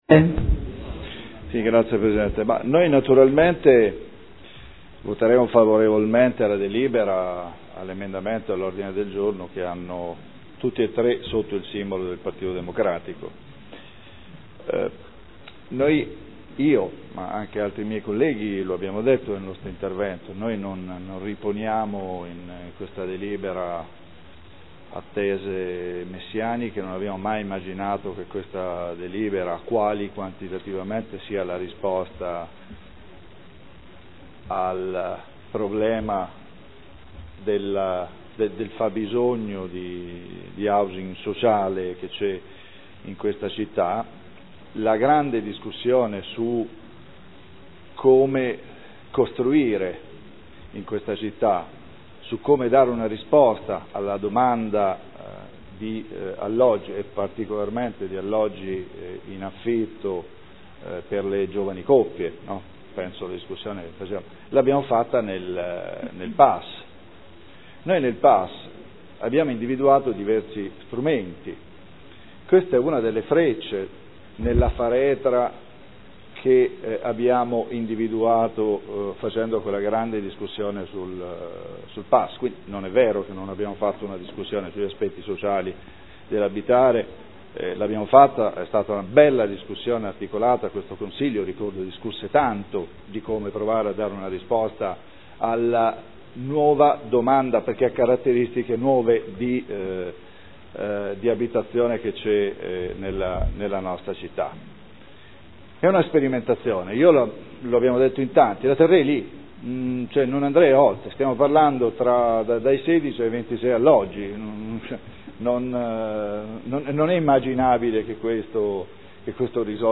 Seduta del 13/05/2013 Dichiarazione di voto.